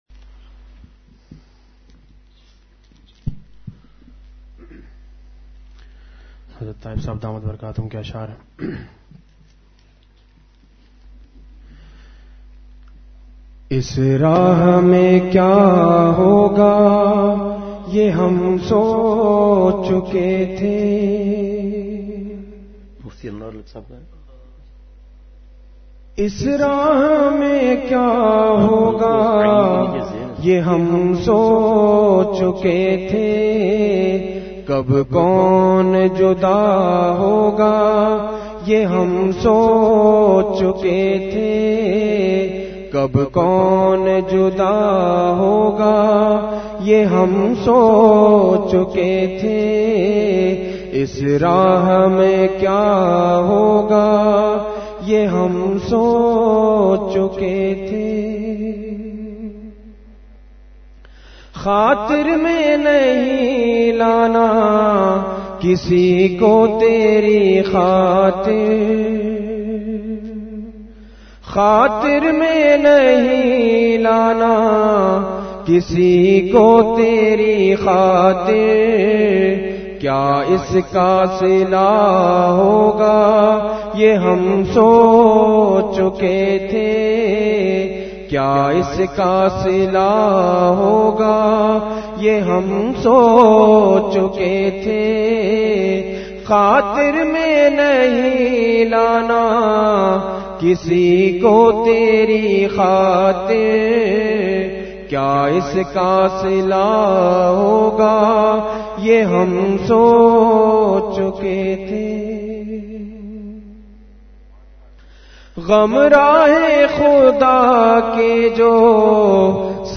اصلاحی مجلس